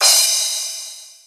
• Cymbal Sound G Key 01.wav
Royality free cymbal sound clip tuned to the G note. Loudest frequency: 6155Hz
cymbal-sound-g-key-01-rlS.wav